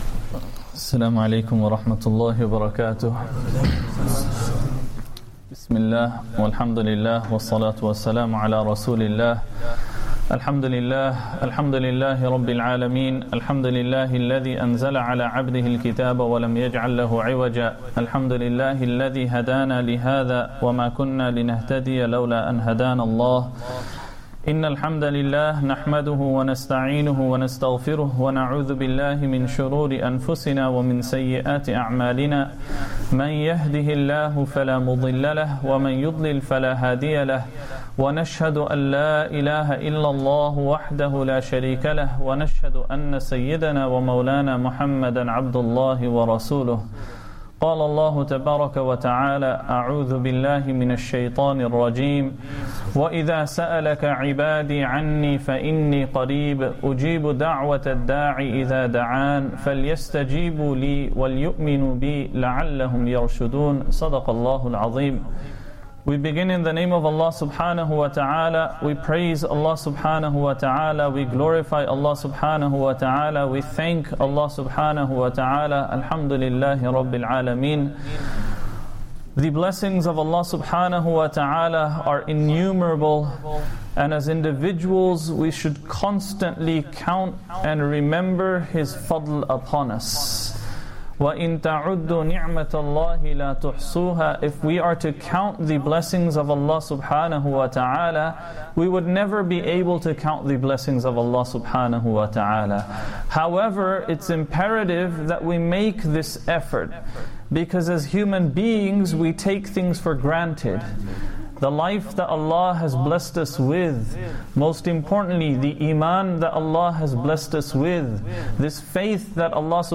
Masjid Adam, Ilford